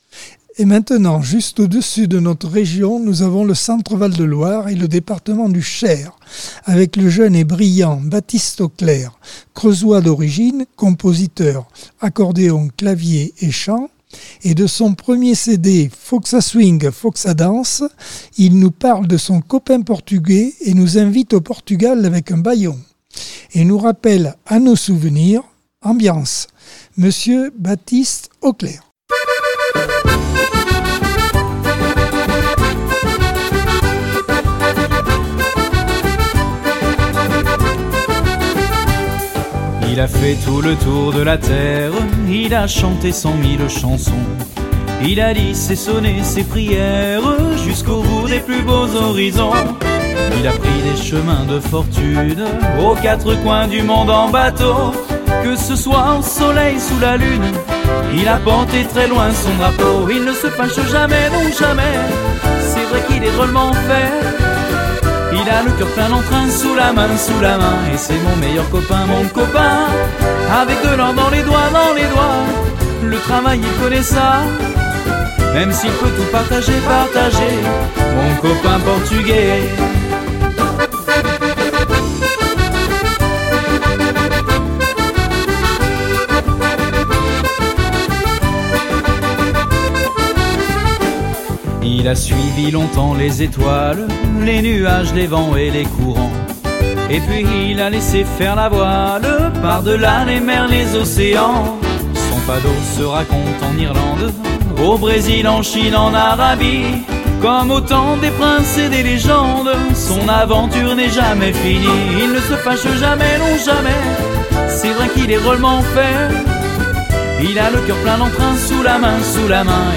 Accordeon 2024 sem 33 bloc 2 - Radio ACX